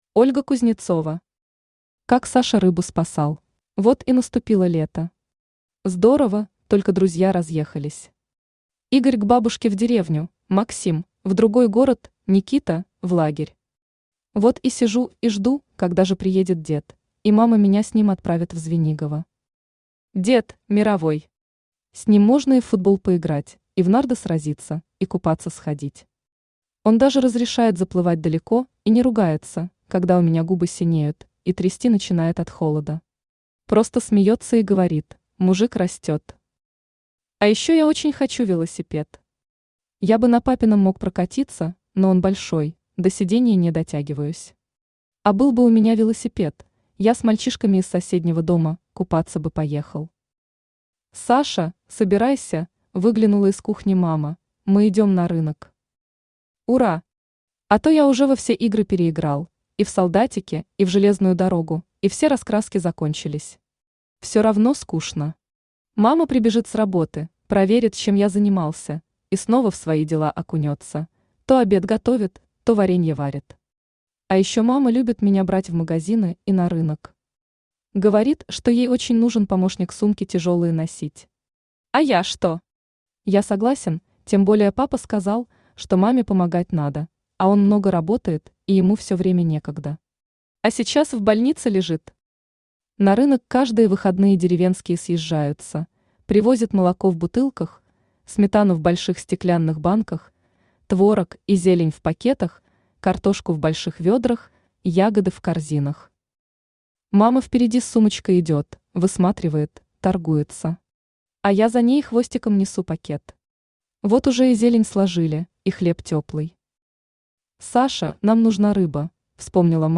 Аудиокнига Как Саша рыбу спасал | Библиотека аудиокниг
Aудиокнига Как Саша рыбу спасал Автор Ольга Кузнецова Читает аудиокнигу Авточтец ЛитРес.